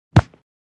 collapse3.wav